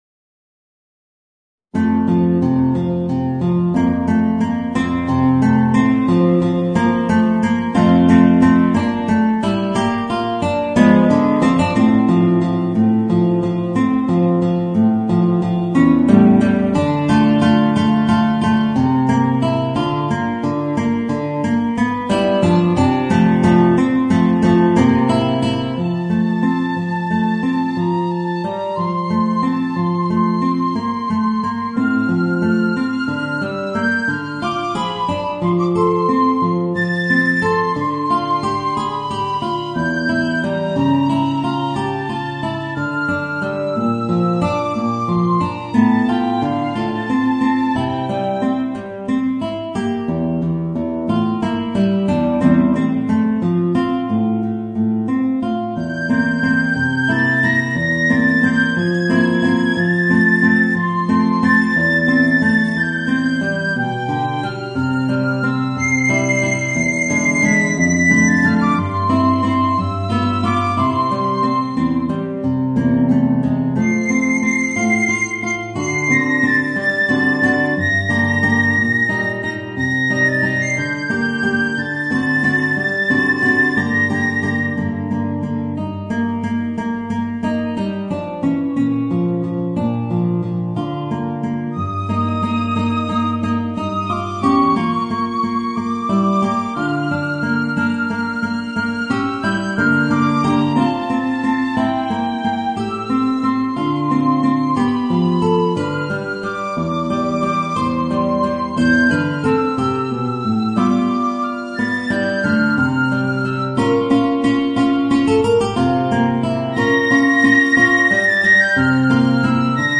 Voicing: Guitar and Piccolo